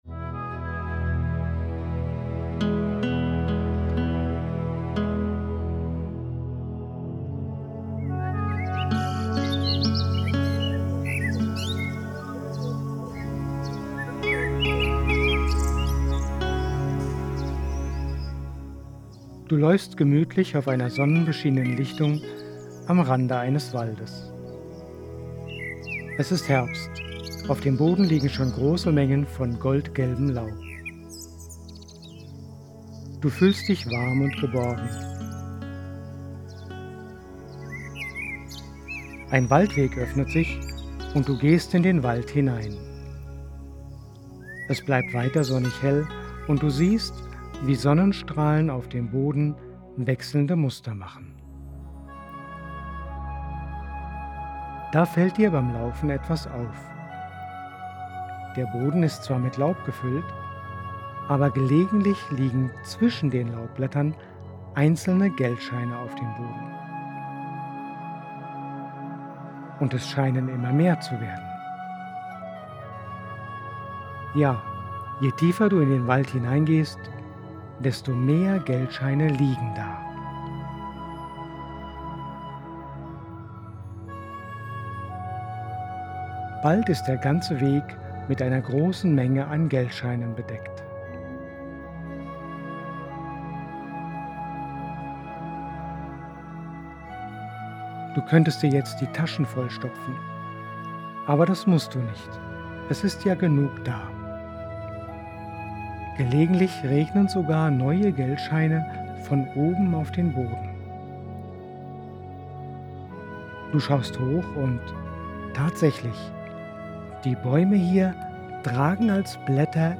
Hier kannst du eine sechs Minuten kurze Phantasiereise anhören, die ich zu dieser Affirmation für dich geschrieben und aufgenommen habe. Zum Anhören setzt du dich am besten bequem hin und machst die Augen zu, damit du dir den beschriebenen Waldspaziergang gut vorstellen kannst.
Phantasiereise_Reichtum.mp3